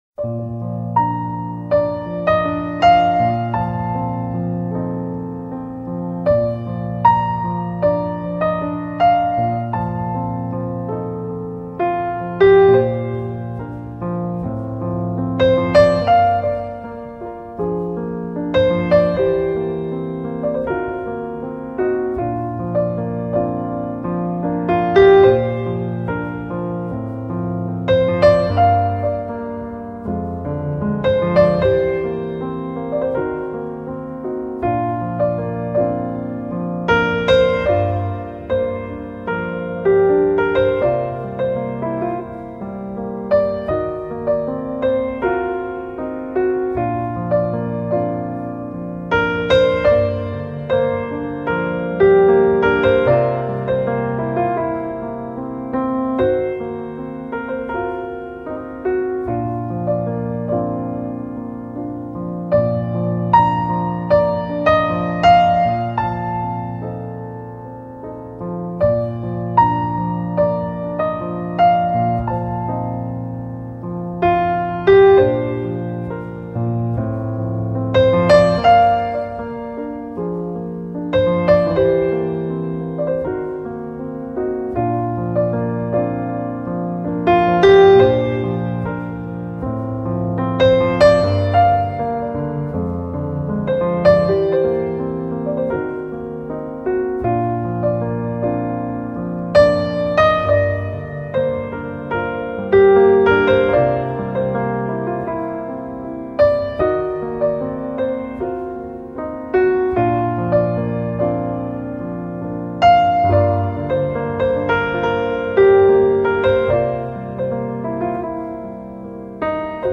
熟悉的旋律重温回忆中的浪漫，悠扬的音符盘旋在寂静的空中。